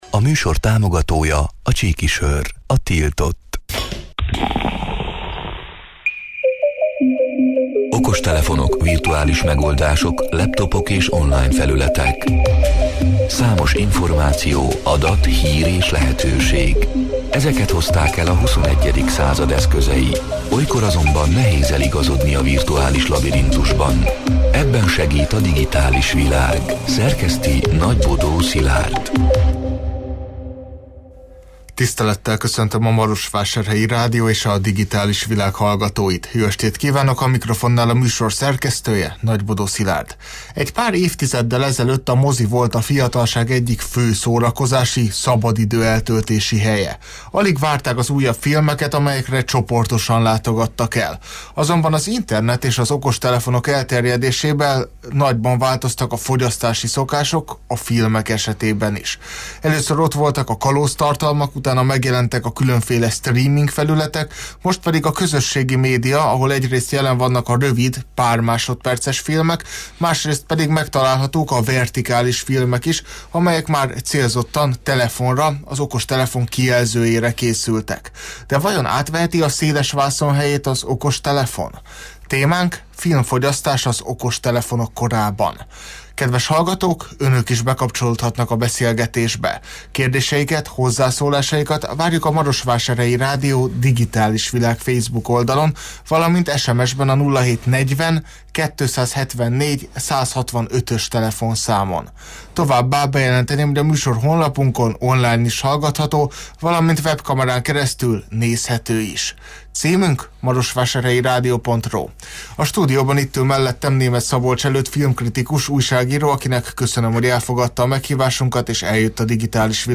A Marosvásárhelyi Rádió Digitális Világ (elhangzott: 2025.január 14-én, kedden este nyolc órától élőben) c. műsorának hanganyaga: